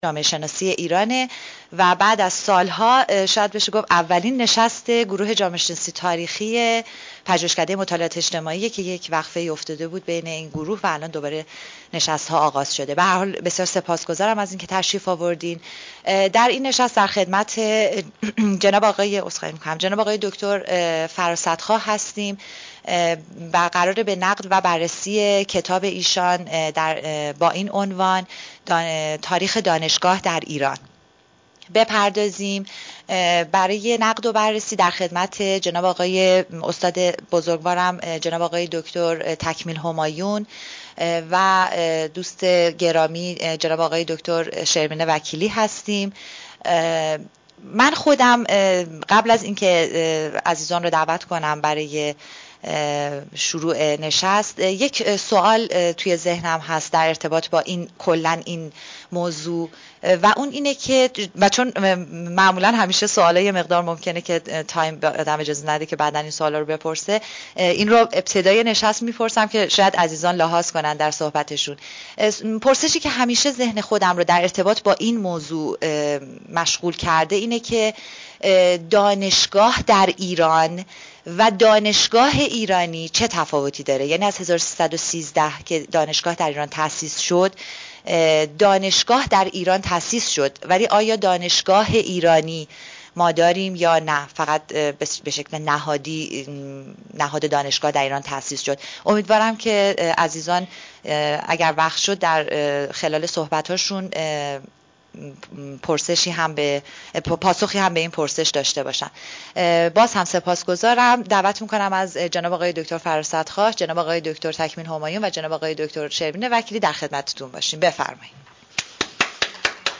پژوهشکده مطالعات اجتماعی با همکاری گروه جامعه شناسی تاریخی انجمن جامعه شناسی ایران برگزار می کند: نشست نقد و بررسی کتاب تاریخ دانشگاه در ایران...